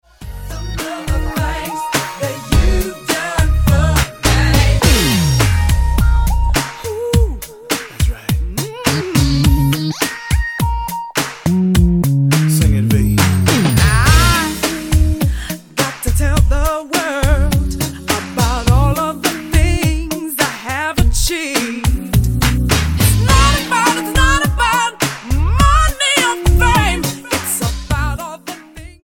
Latin-tinged R&B gospel from a US-based duo R&B gospel?
Style: R&B